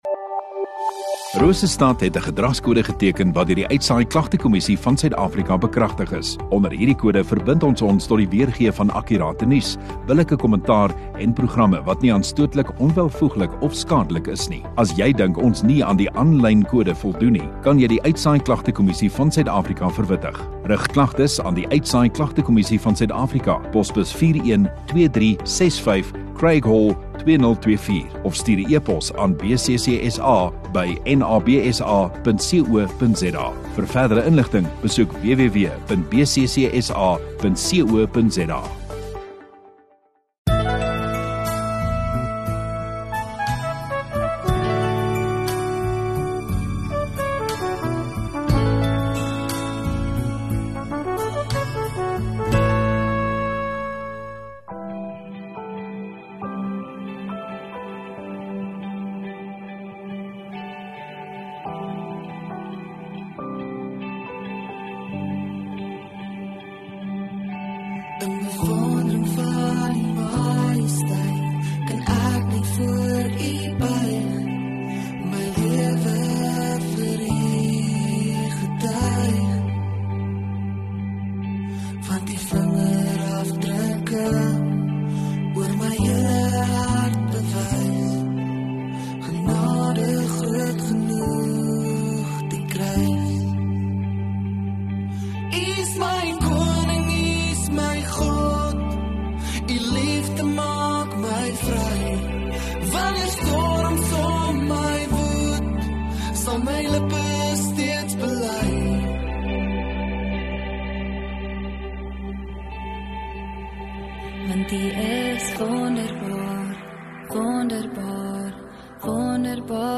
23 Feb Sondagaand Erediens